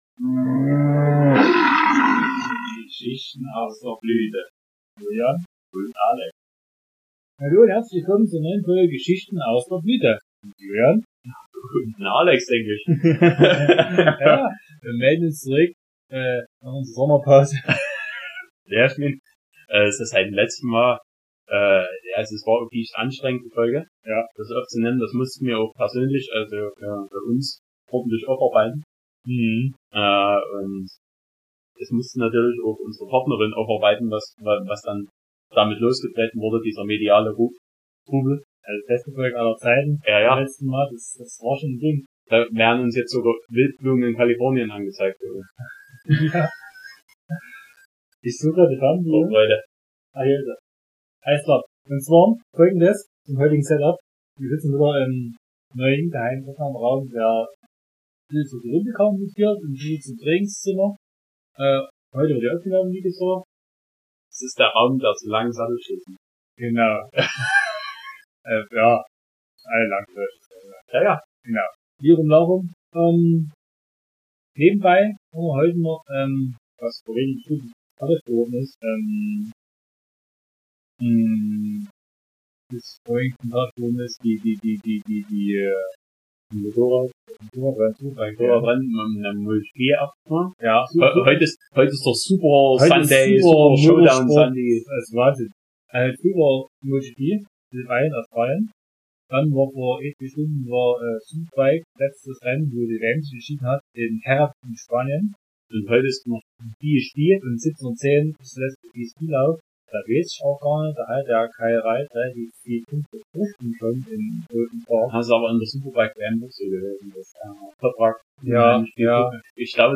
Zwei stolze Burschen aus dem Herzen der Zone, Karl Marx City!
Es dreht sich viel um Motorradrennen, von denen ihr alle keine Ahnung habt und euch auch nicht dafür Interessiert… 😜 Egal, zum sächsisch lernen für junge Hörakustiker*innen geht auch planloses Biergelaber über Männer in Tierhäuten die im Kreis fahren.